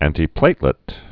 (ăntē-plātlĭt, ăntī-)